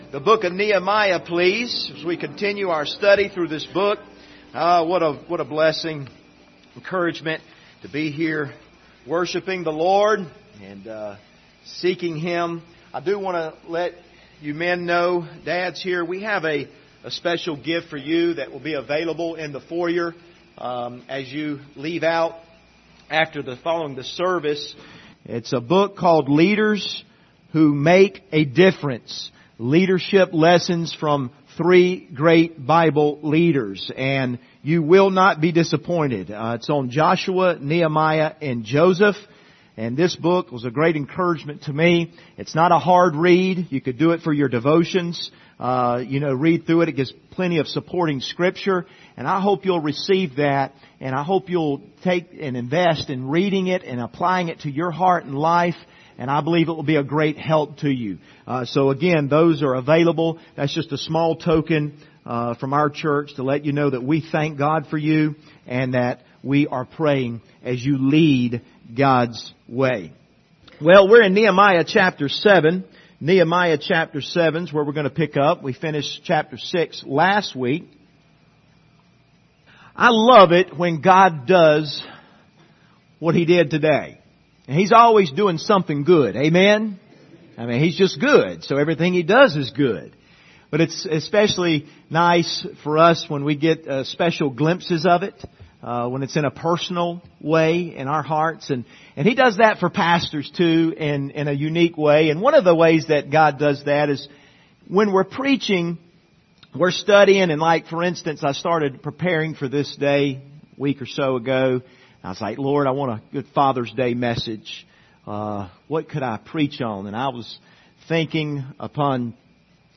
Passage: Nehemiah 7:1-4 Service Type: Sunday Morning View the video on Facebook « Protected